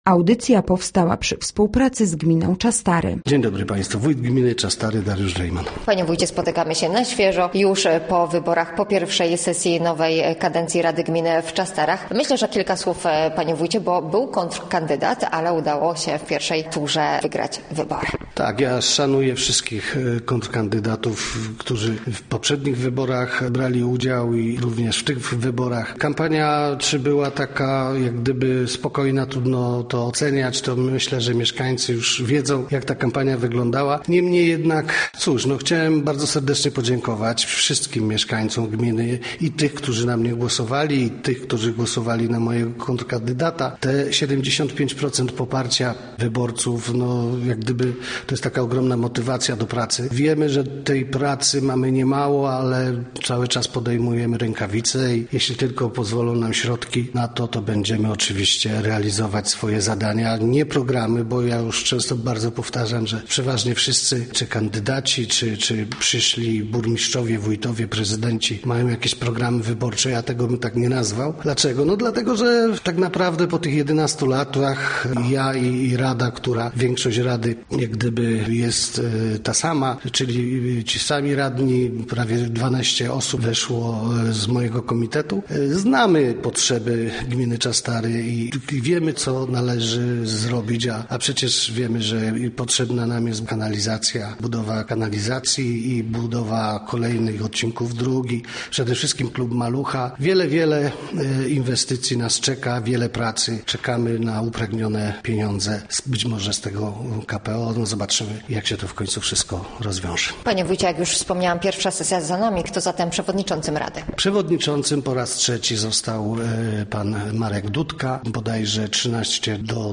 Gościem Radia ZW był Dariusz Rejman, wójt gminy Czastary